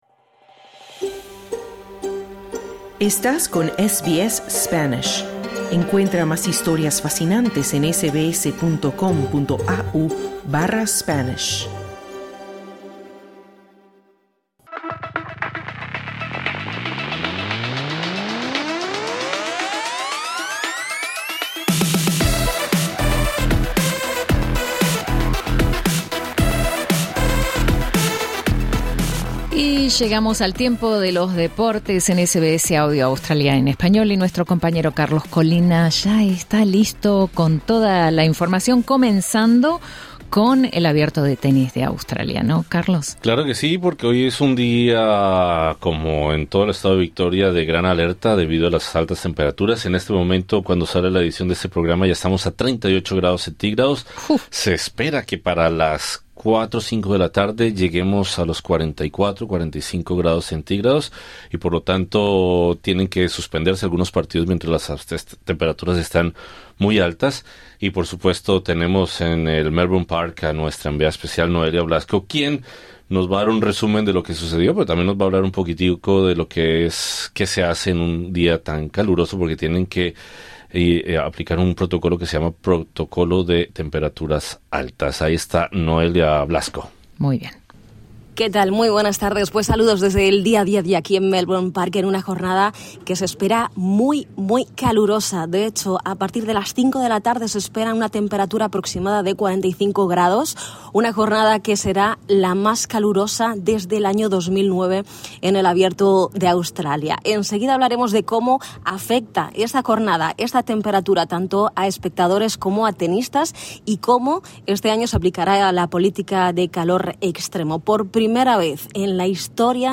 El Abierto de Australia vuelve a utilizar el protocolo por "calores extremos", con temperaturas que superarán los 44ºC. Escucha el resumen deportivo de este martes 27 de enero 2026.